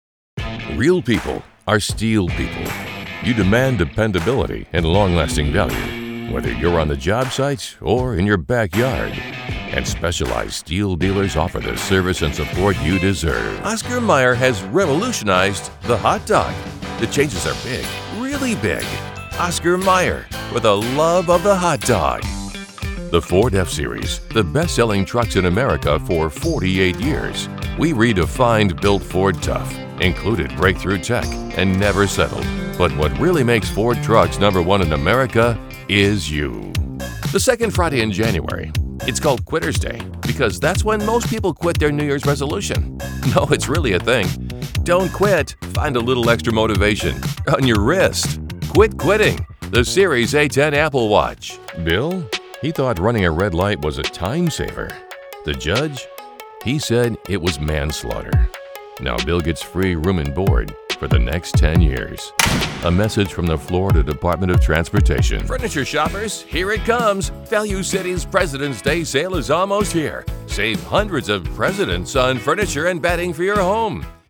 Conversational - Smooth - Powerful - Sincere
Commercial-Demo.mp3